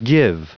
Prononciation du mot give en anglais (fichier audio)
Prononciation du mot : give